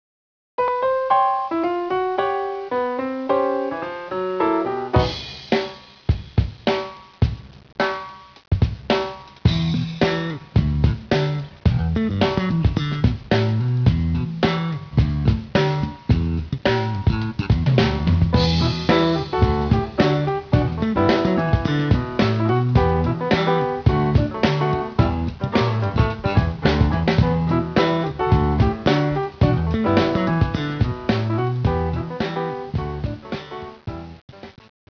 The 4 string electric bass solo